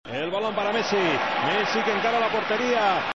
「メシーかメシやな」